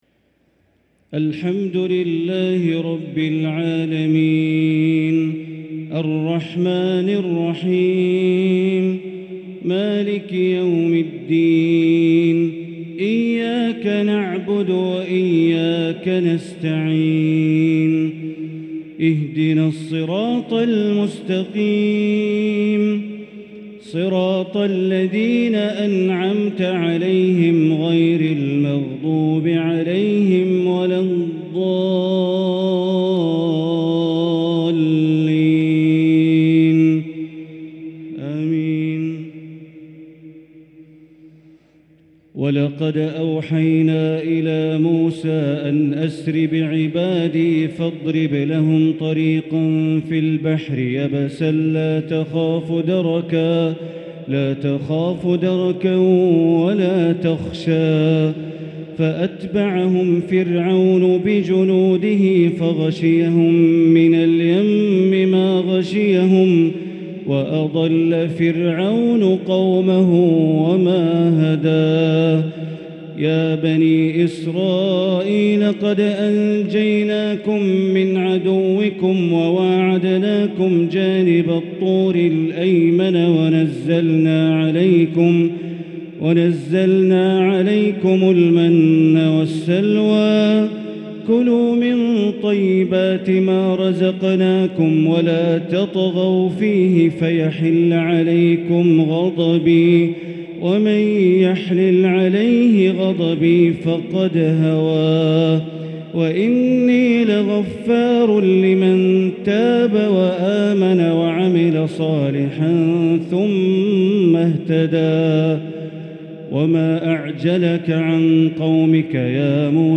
تهجد ليلة 21 رمضان 1444هـ من سورتي طه (77-135) و الأنبياء (1-96) | Tahajjud 21st night Ramadan 1444H Surah Taha and Al-Anbiya > تراويح الحرم المكي عام 1444 🕋 > التراويح - تلاوات الحرمين